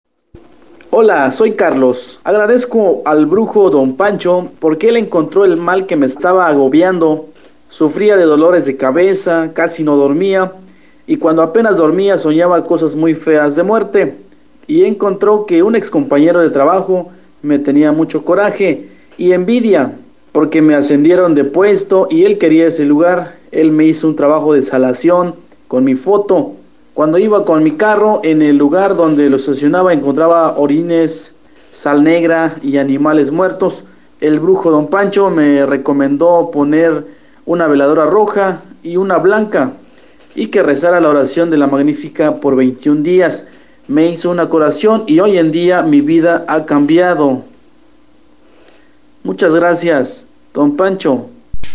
Testimonios reales